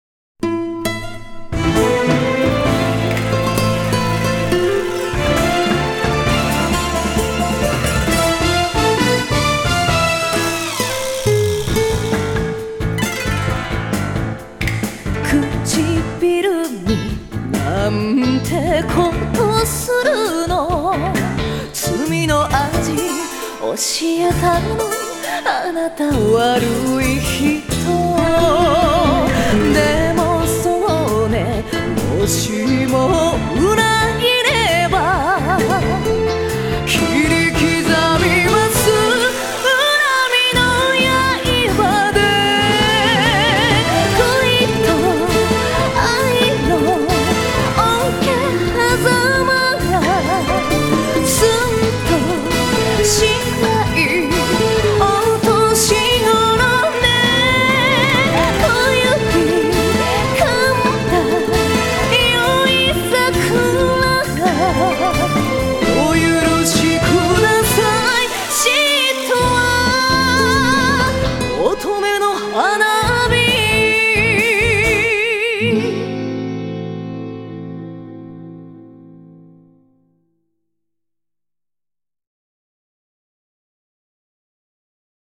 Ici l'extrait d'une chanson.